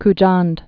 (k-jänd)